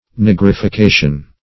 Search Result for " nigrification" : The Collaborative International Dictionary of English v.0.48: Nigrification \Nig`ri*fi*ca"tion\, n. [L. nigrificare to blacken; niger black + -ficare (in comp.) to make.